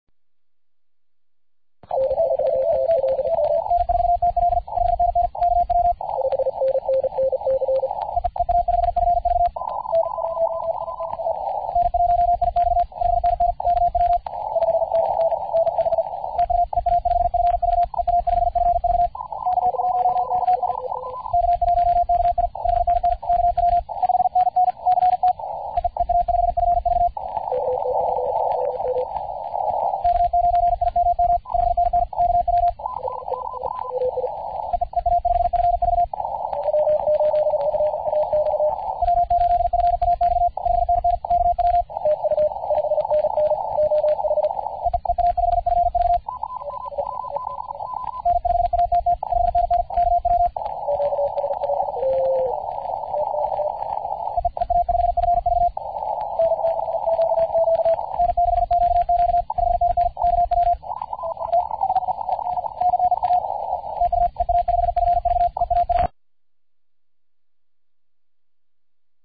Varios SO1R, 7 QSO's por minuto CW !
ARRL2007_TotalOrgasm_7QSO.mp3